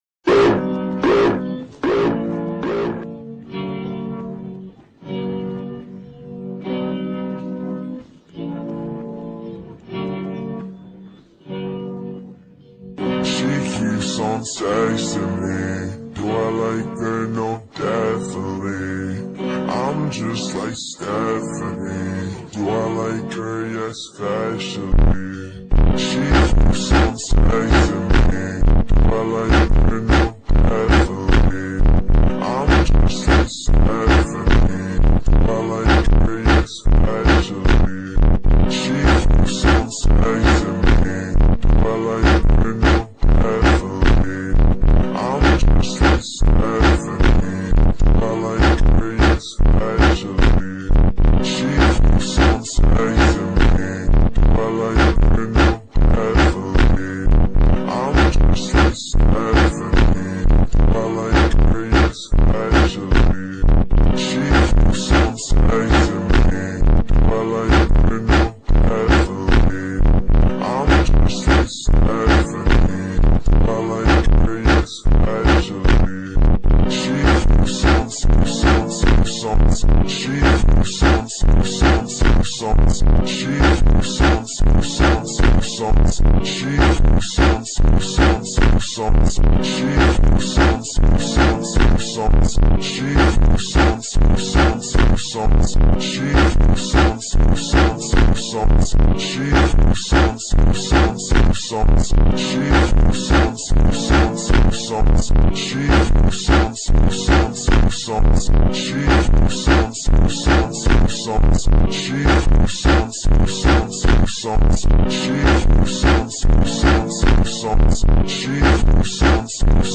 با ریتمی کند شده
فانک